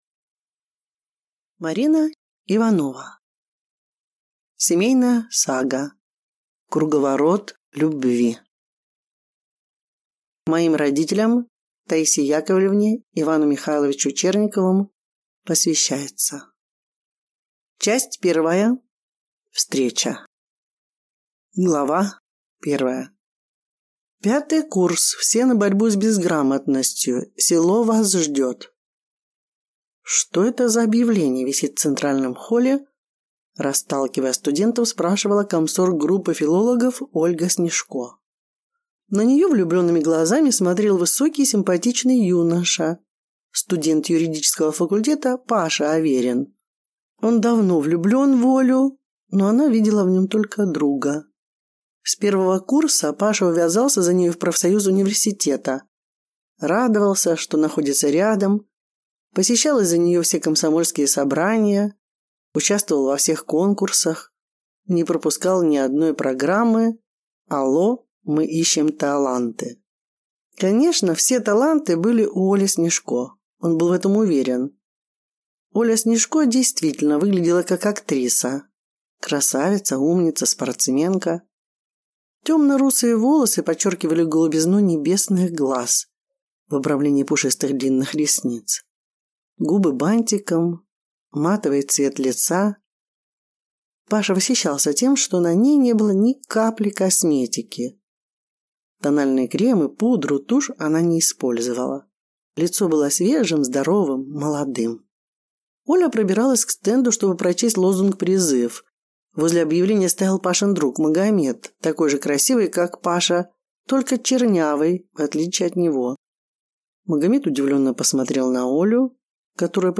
Аудиокнига Круговорот любви | Библиотека аудиокниг